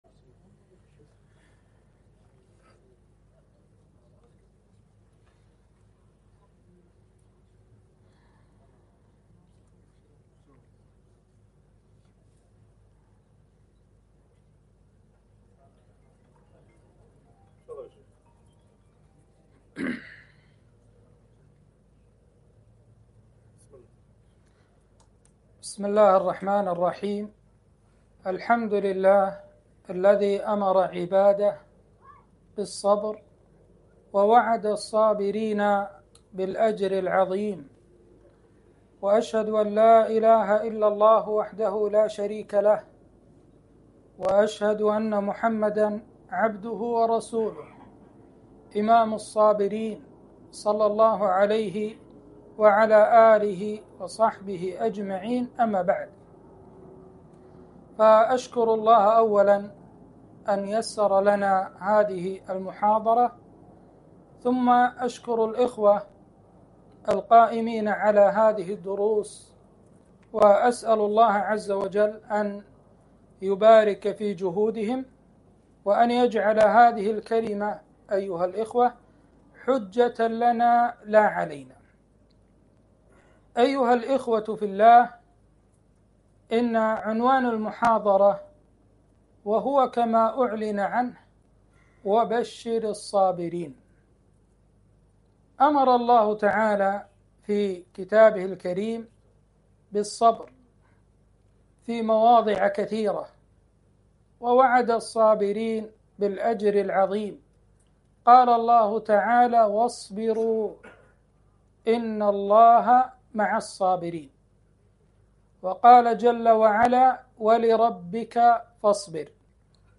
محاضرة - ( وبشر الصابرين )